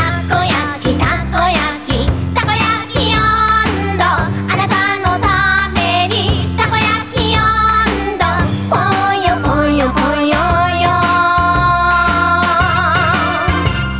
盆踊りや運動会でひっぱり蛸のメロディー。 一度聞いたら忘れられないフレーズ。